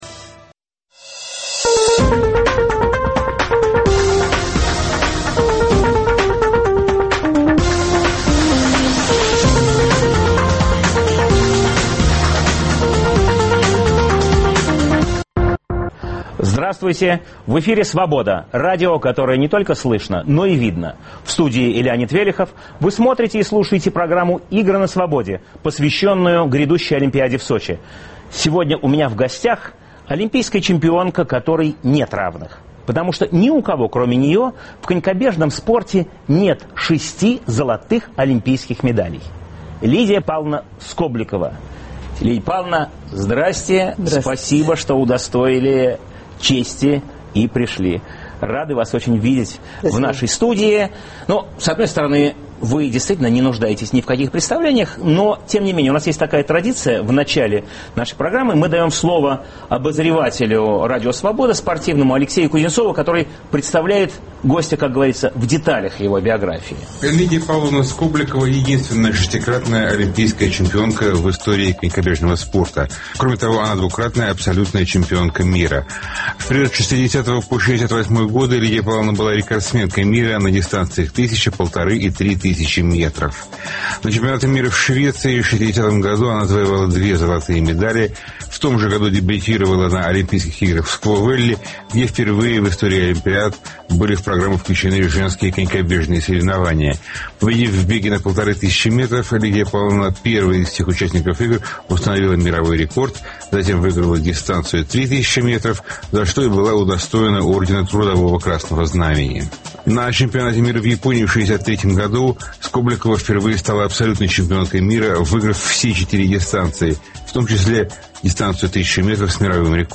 Лидия Скобликова. Равных не было и нет. В программе интервью с шестикратной олимпийской чемпионкой, конькобежкой Лидией Скобликовой.